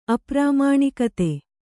♪ aprāmāṇikate